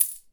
coin2.ogg